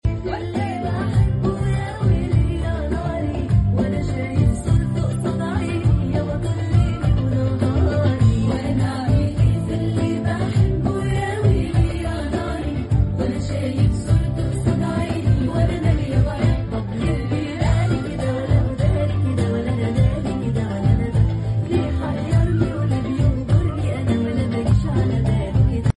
أحمد داش بيقلد روبي في فرح اخوه